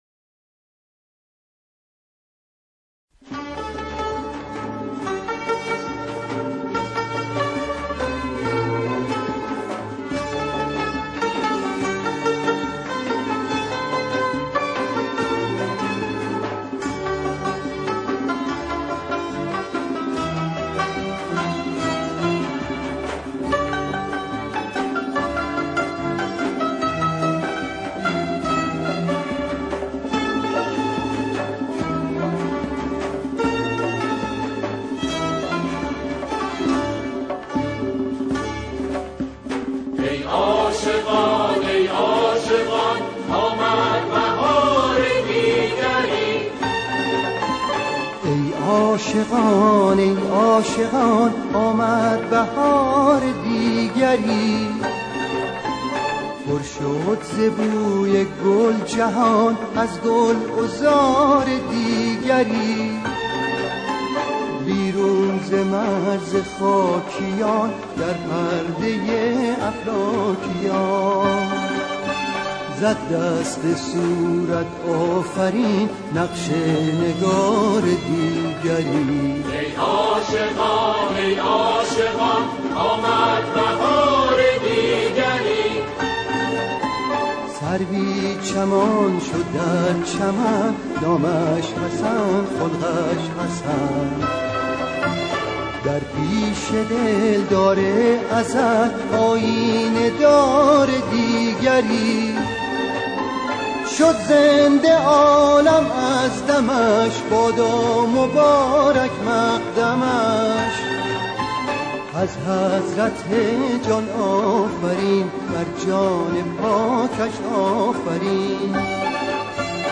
سرودهای امام حسن مجتبی علیه السلام